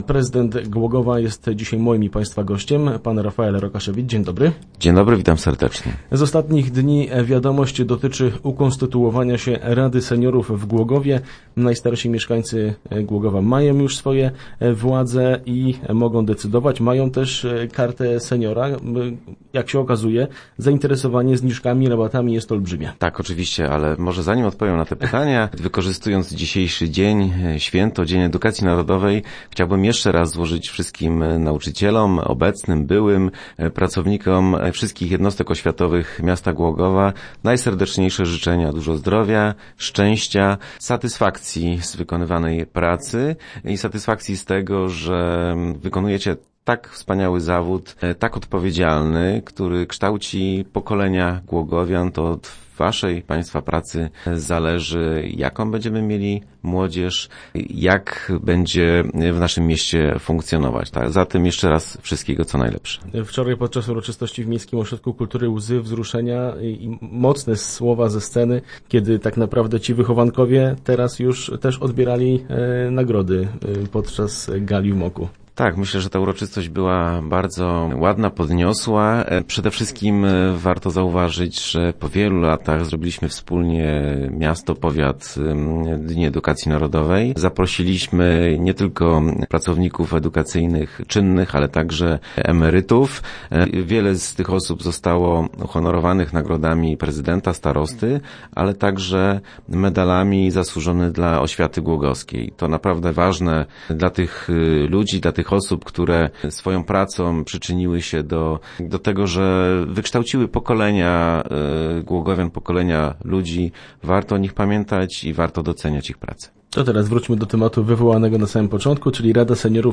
1014_re_rok.jpgW Dniu Edukacji Narodowej w radiowym studiu gościł prezydent Głogowa Rafael Rokaszewicz. Audycję rozpoczęliśmy od złożenia życzeń dla wszystkich pracowników oświaty w mieście.